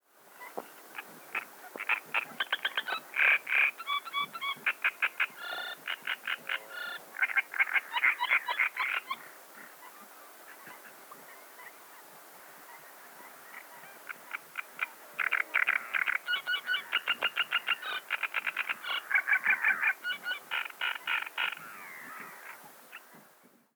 마크타 습지(알제리)에서 녹음된 노래